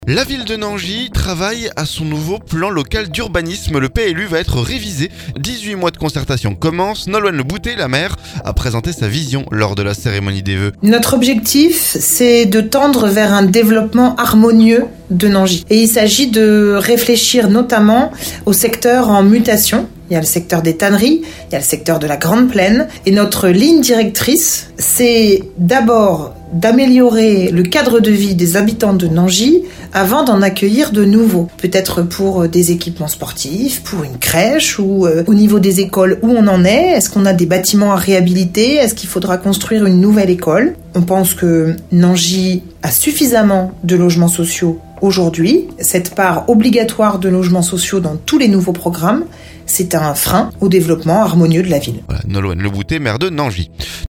Nolwenn Le Bouter, la maire, a présenté sa vision lors de la cérémonie des vœux.